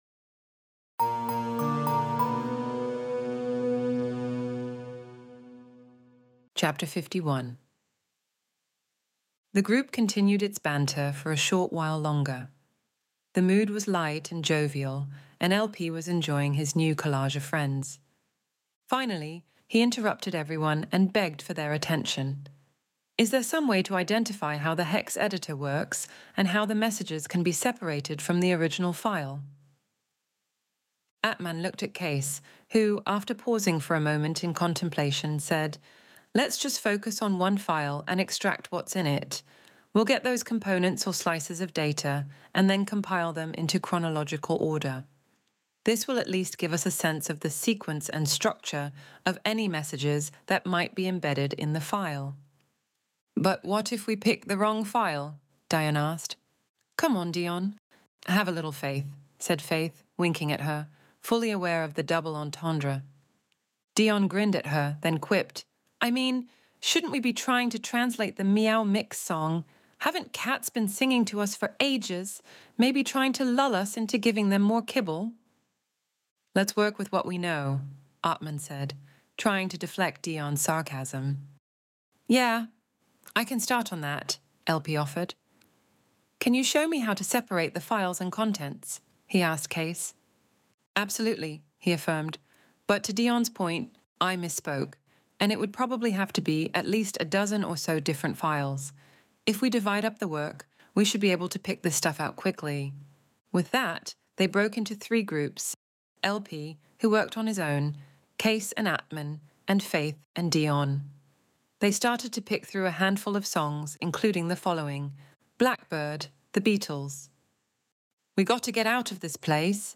Extinction Event Audiobook